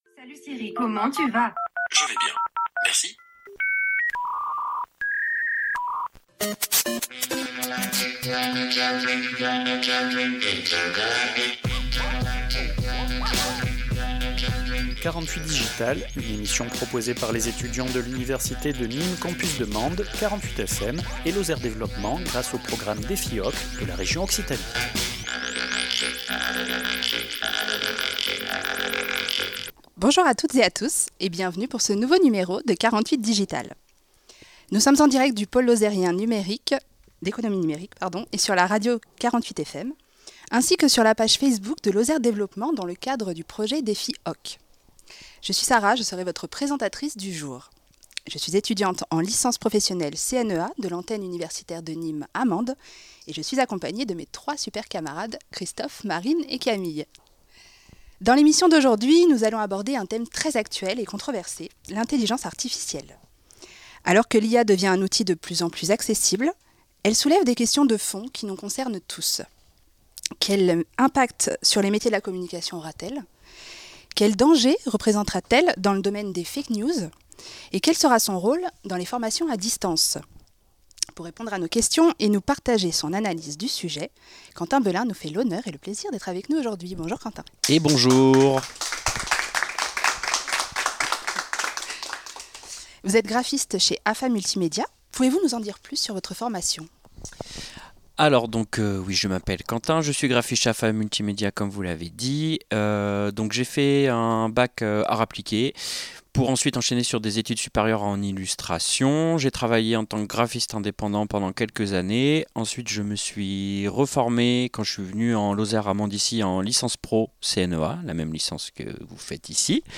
Une émission proposée par 48FM, l’Université de Nîmes antenne de Mende et Lozère développement
En direct de Polen